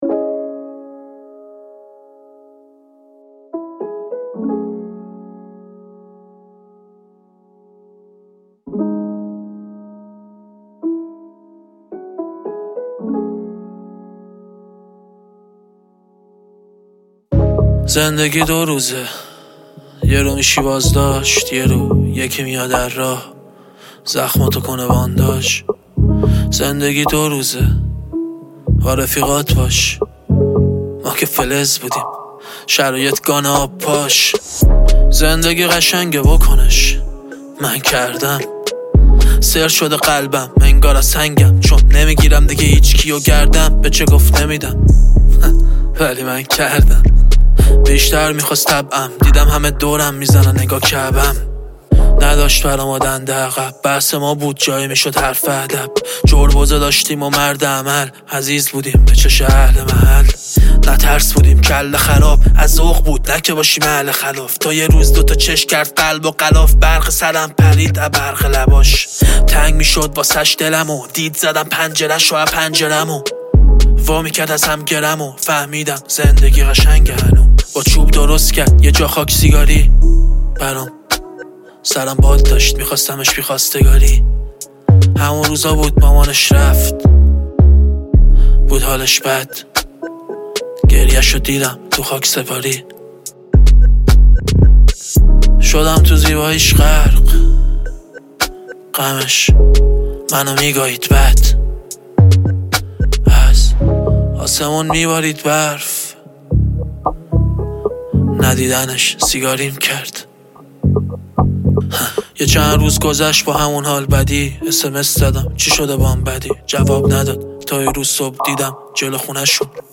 رپ
تک آهنگ